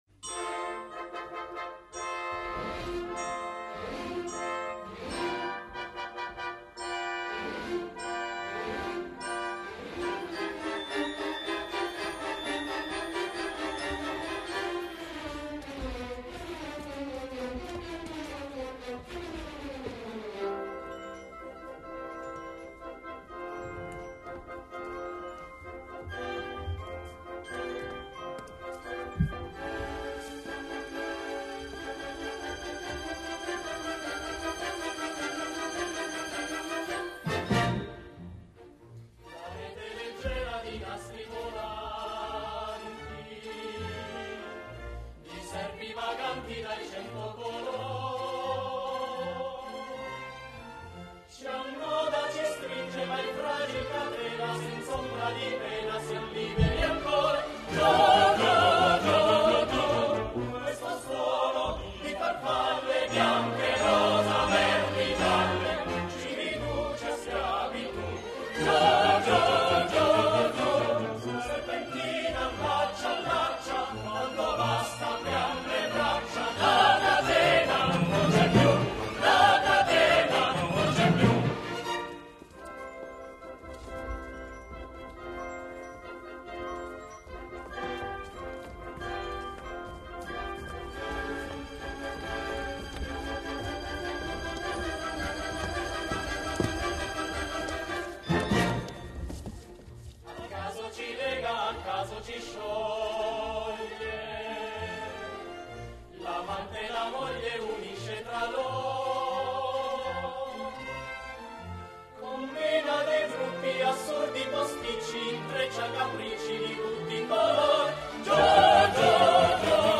»operetta / Operette«
coro